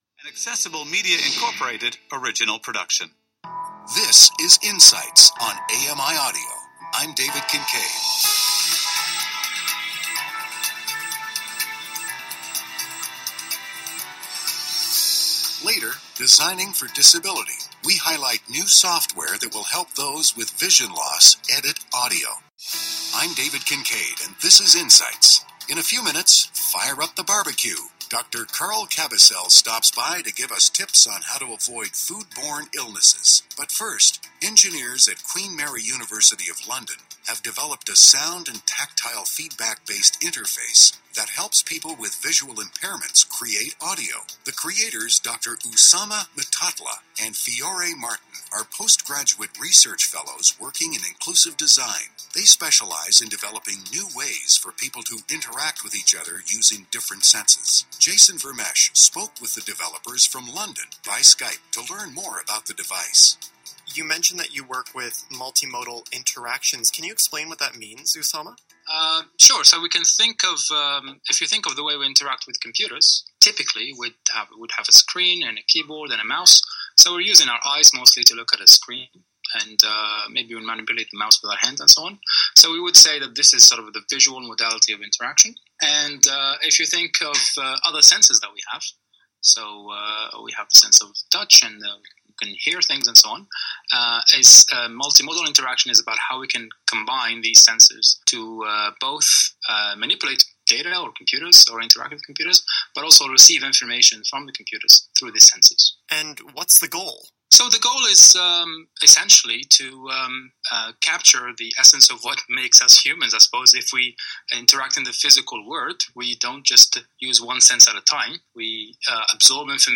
Interviewed by Accessible Media Inc.
AMI-interview.mp3